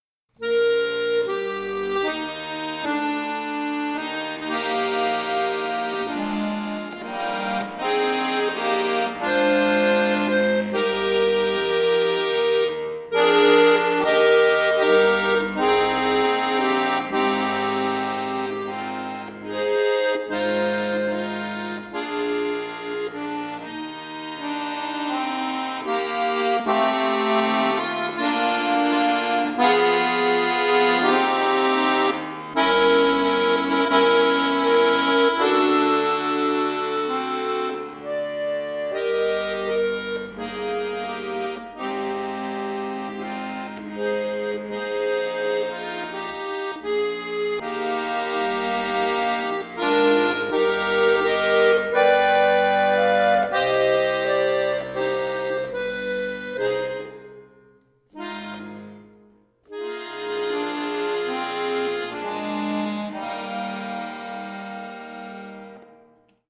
hier in einem mittelschweren Arrangement für Akkordeon solo
Klassisch, Volkslied